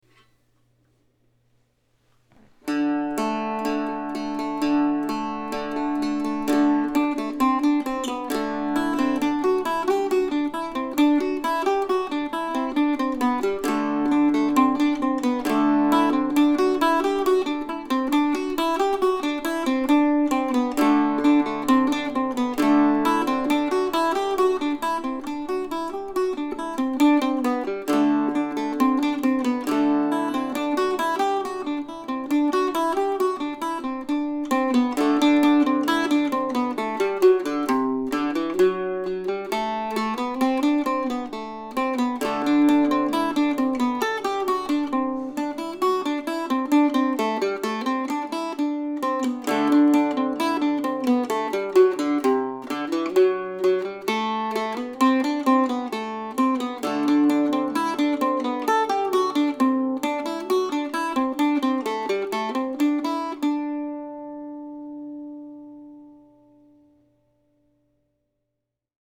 Summer Suite, August, 2020 (for Octave Mandolin or Mandocello)
I added short introductions to a couple of the pieces, I changed keys a couple of times and I added drones here and there.